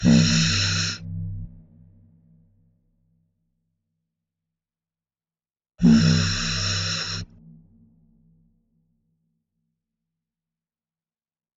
Tag: 83 bpm Hip Hop Loops Synth Loops 1.95 MB wav Key : C